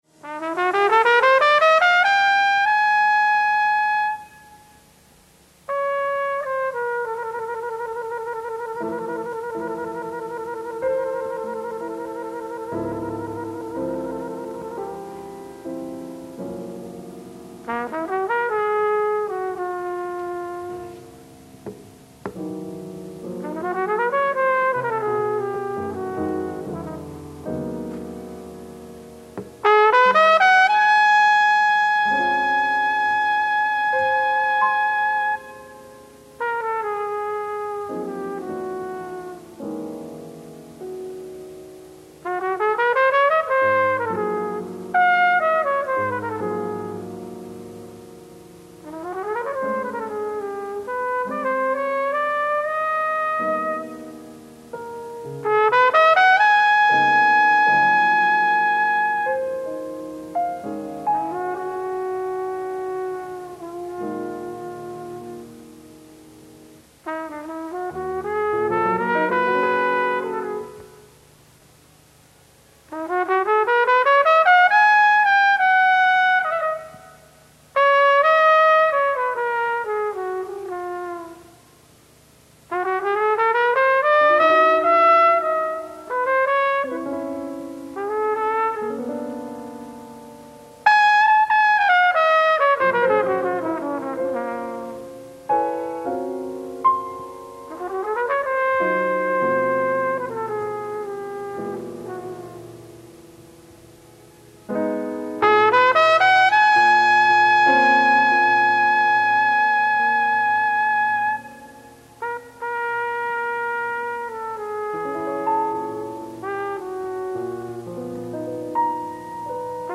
Live At Konserthuset, Stockholm, Sweden 10/31/1967